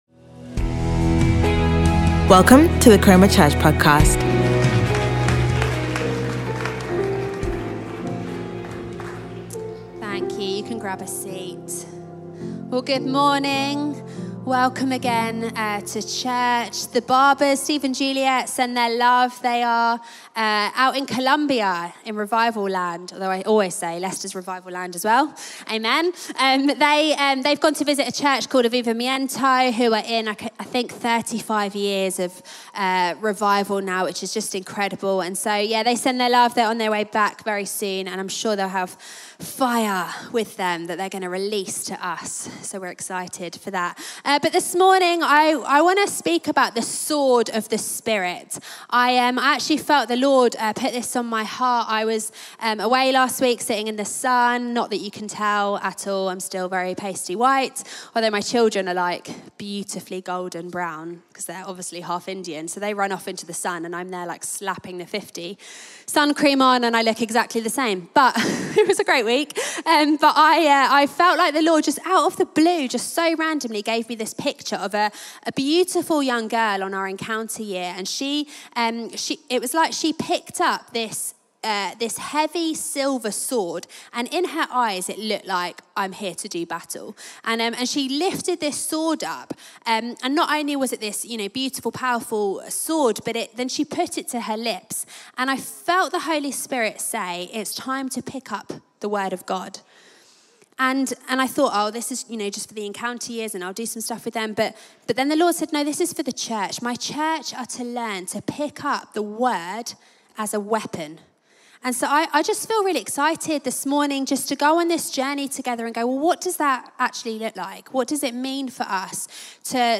Chroma Church Live Stream
Sunday Sermon The Sword Of The Spirit